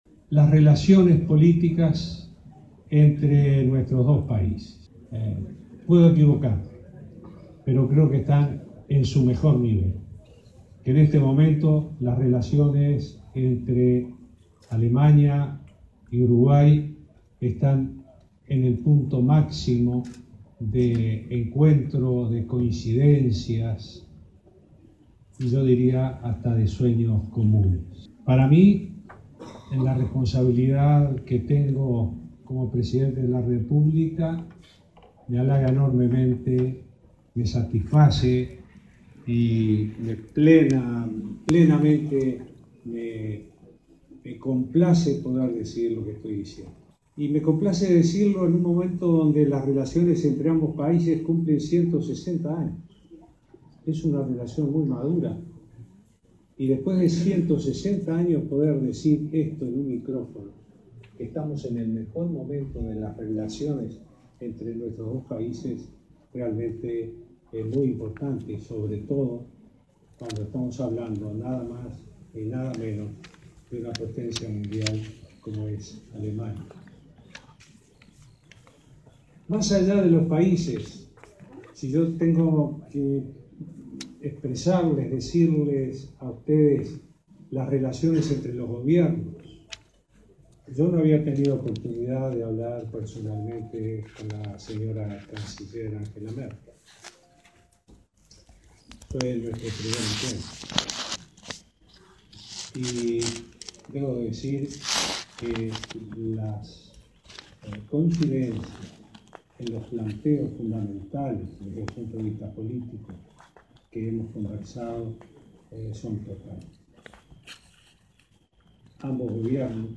Uruguay y Alemania se encuentran en el momento más positivo de sus relaciones en 160 años de historia diplomática, según destacó el Presidente Tabaré Vázquez en la noche del viernes 10 en Hamburgo. El mandatario participó en La Noche Gastronómica de Uruguay en el hotel Elyseé, donde mantuvo un encuentro con la colectividad uruguaya en Alemania.